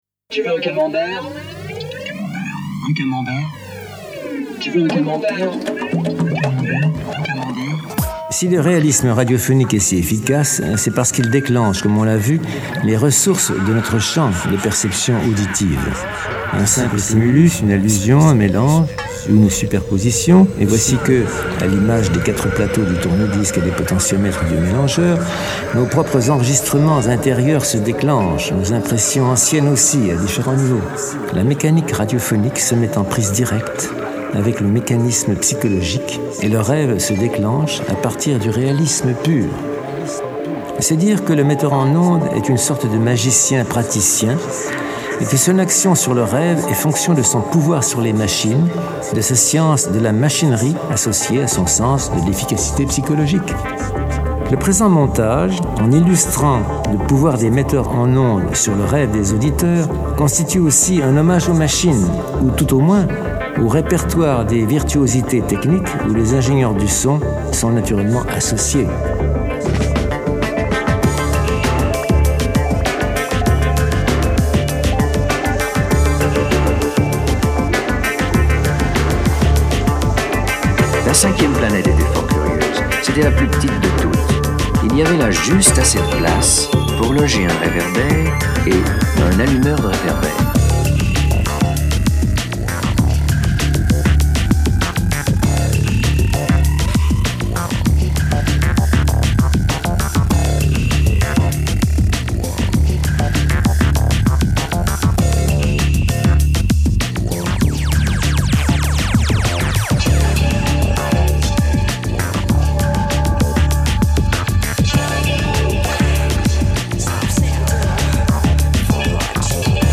;) diffusion originale : radio Accords (Poitiers) le 30.08.1995, de 22h30 à 23h30 spécificités : crac ! paf ! oulala !... reprend trez esprits ! genre : trance nawak express --------